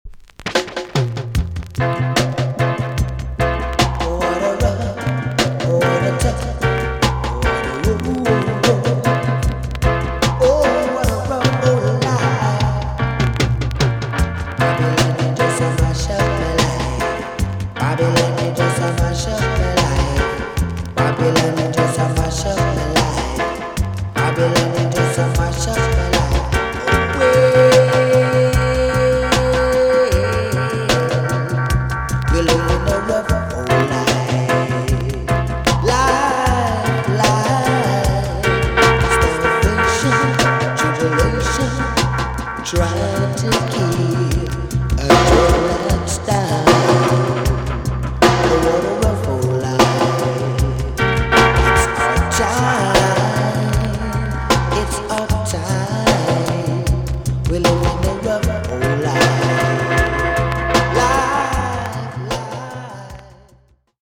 TOP >80'S 90'S DANCEHALL
VG+ 少し軽いチリノイズがあります。